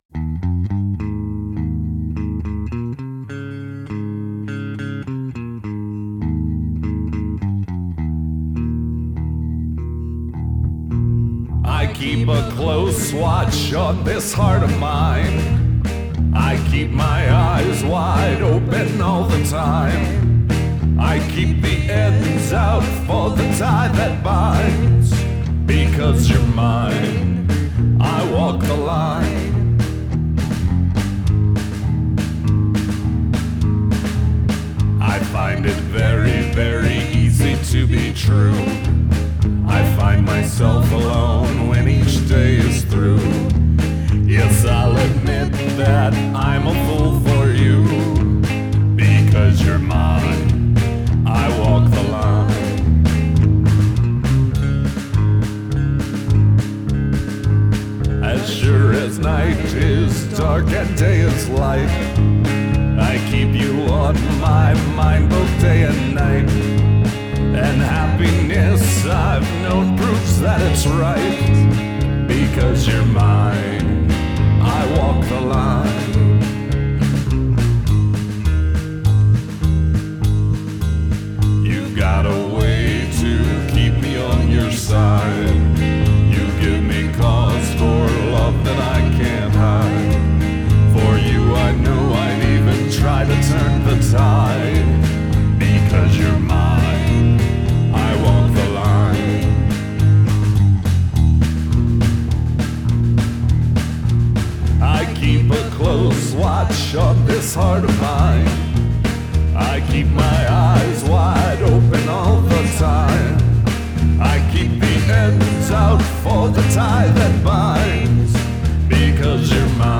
guitar + vocals
bass + vocals
drums